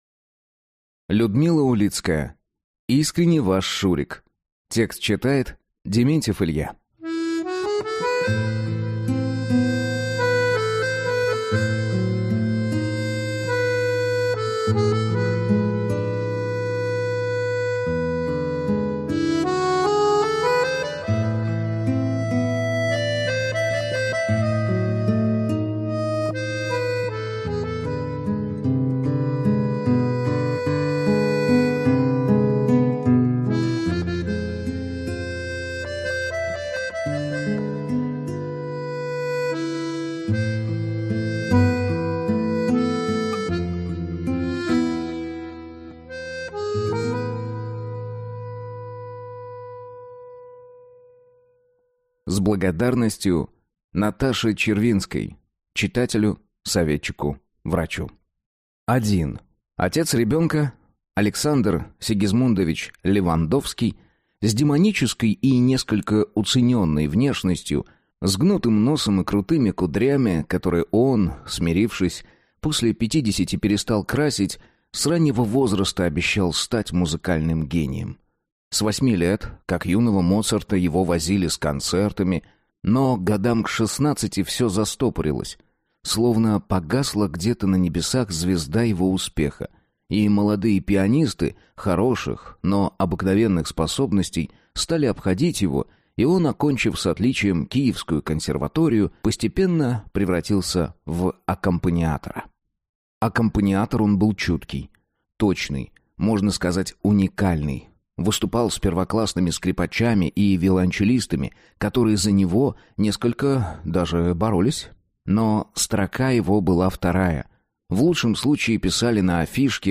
Аудиокнига Искренне ваш Шурик | Библиотека аудиокниг